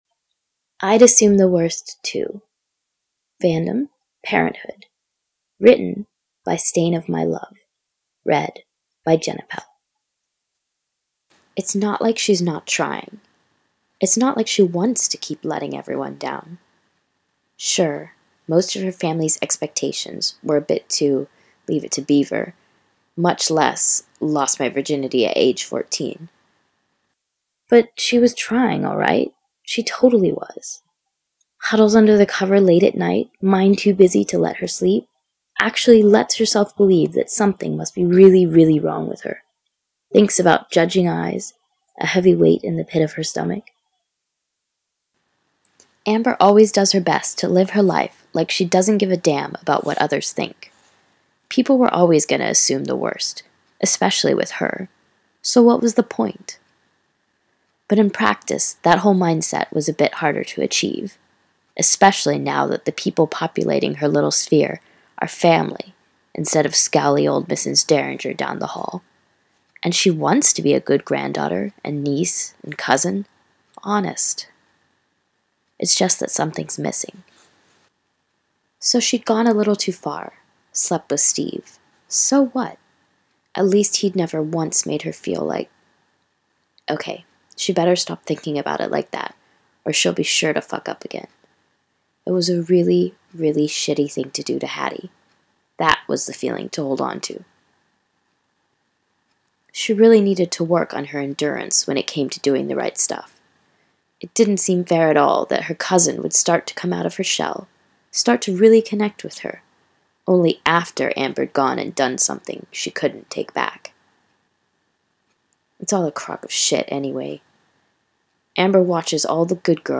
Podfic: 7 shorts for the "Awesome Ladies Ficathon"